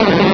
Cri de Chétiflor dans Pokémon Rubis et Saphir.